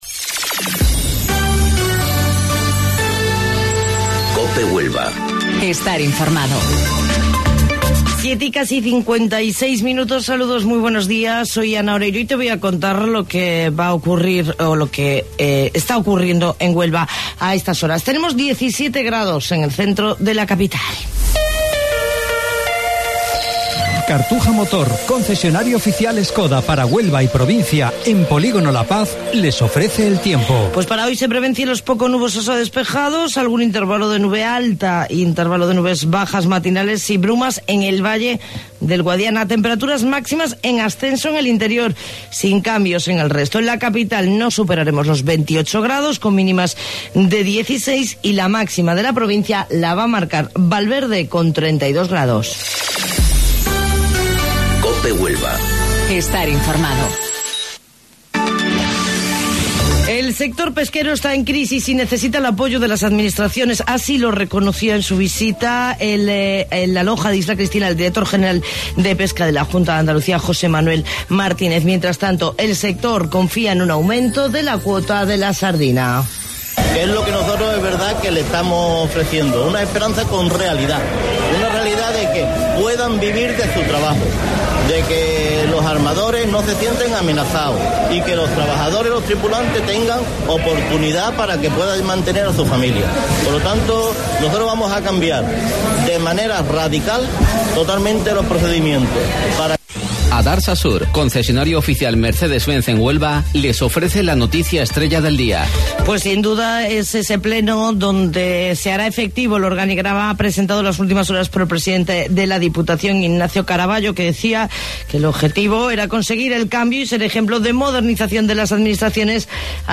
AUDIO: Informativo Local 07:55 del 9 de Julio